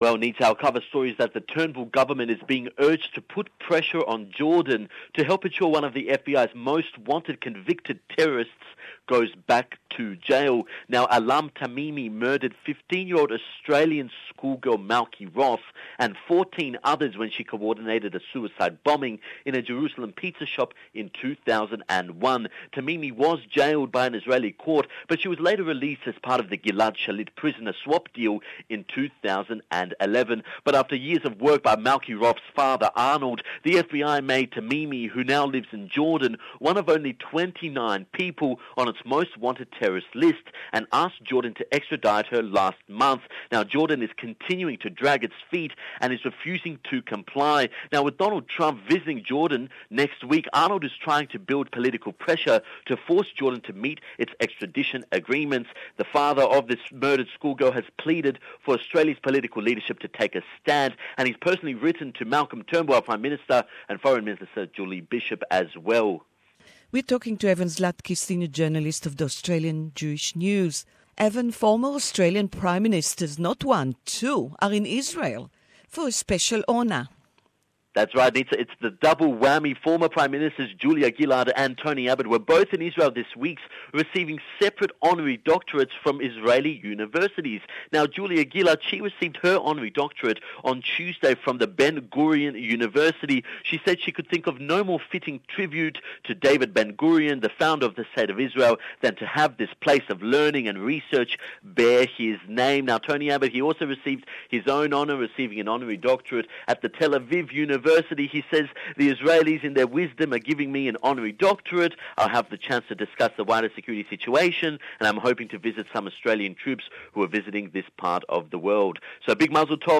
Don't miss our weekly chat with AJN Journalist...find out the latest about the Jewish community around Australia